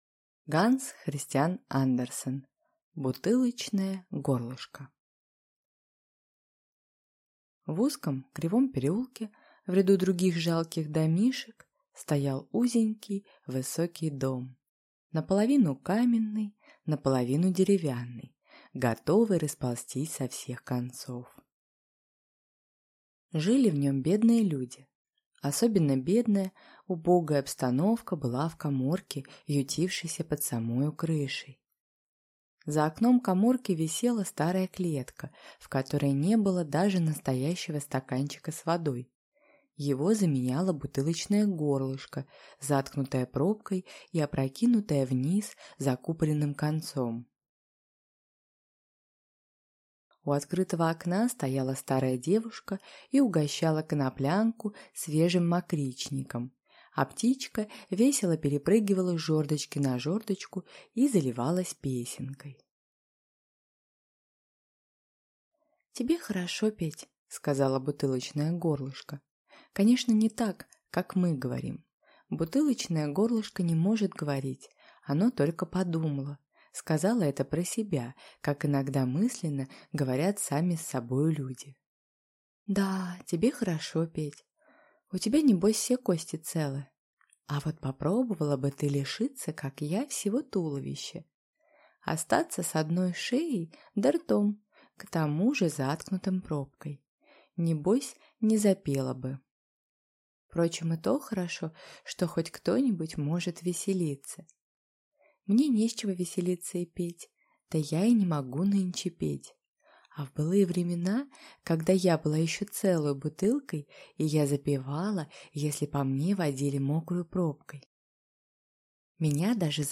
Аудиокнига Бутылочное горлышко | Библиотека аудиокниг